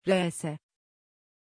Aussprache von Reese
pronunciation-reese-tr.mp3